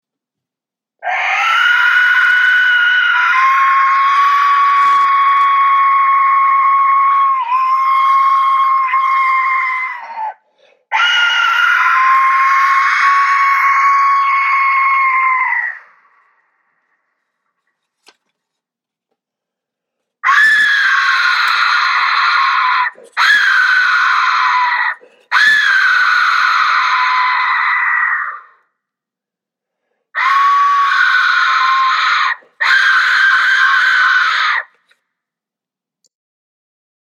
Aztec Death Whistle
Click play for sound of this specific whistle pictured in this page.
Our Aztec Death whistle, is hand tuned to produce the most frightening scariest sound.
Our Aztec Death Whistle is a hand crafted one at a time, these musical instruments produce the most loud, terrifying sound imaginable.